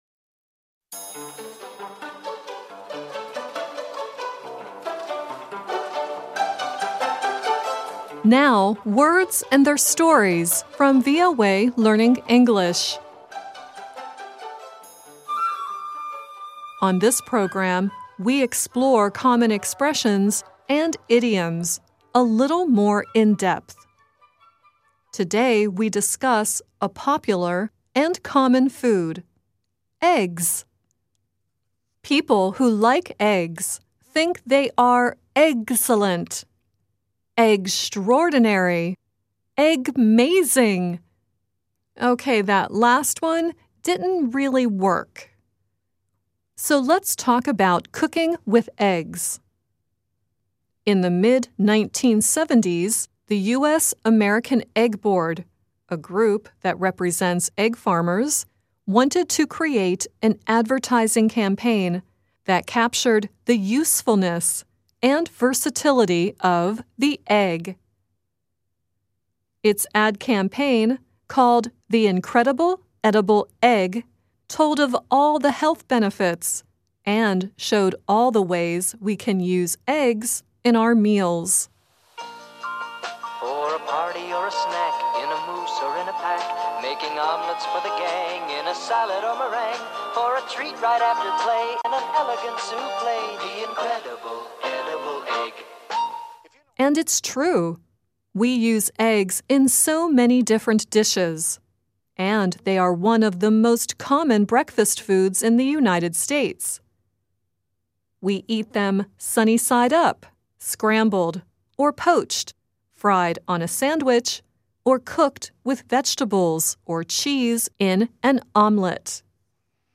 The song at the end is Leo Sayer singing "Can't Make an Omelet Without Eggs" from the movie The Missing Link.